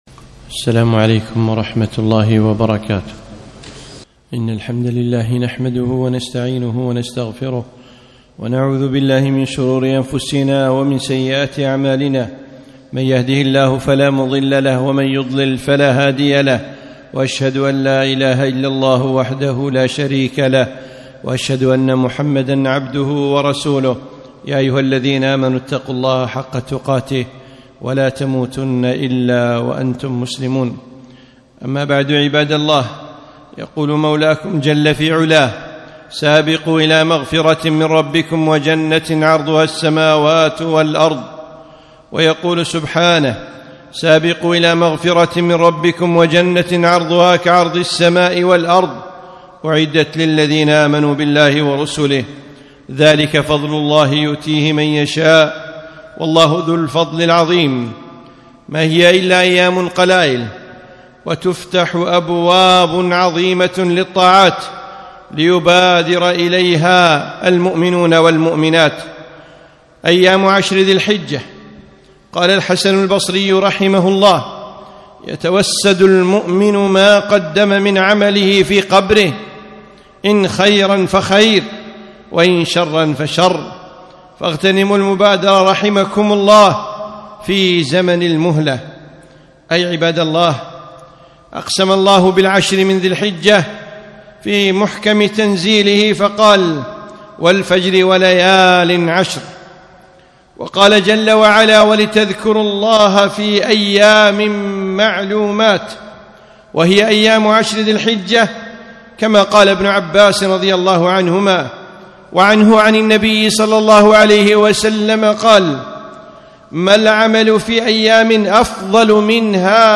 خطبة - أيام العشر